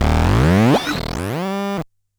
Glitch FX 44.wav